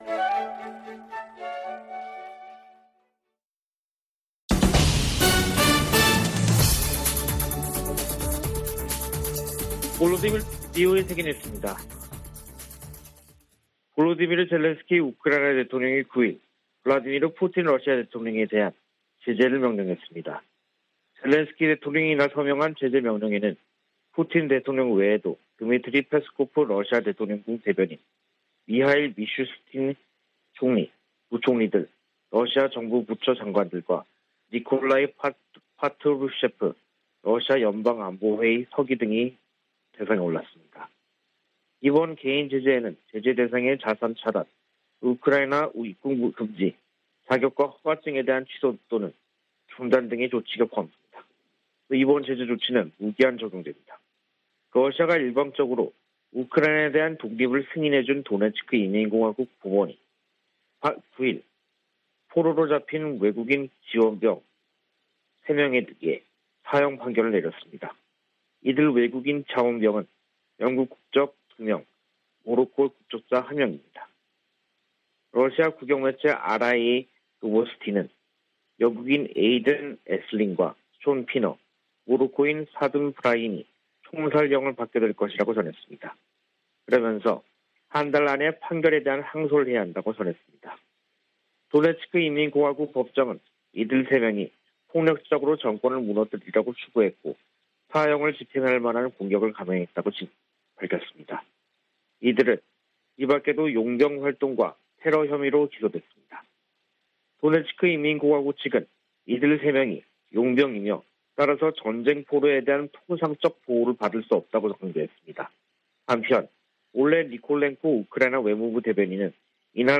VOA 한국어 간판 뉴스 프로그램 '뉴스 투데이', 2022년 6월 10일 3부 방송입니다. 윤석열 한국 대통령이 한국 정상으로는 처음 나토 정상회의에 참석합니다. 북한이 핵실험을 강행하면 억지력 강화, 정보유입 확대 등 체감할수 있는 대응을 해야 한다고 전직 미국 관리들이 촉구했습니다.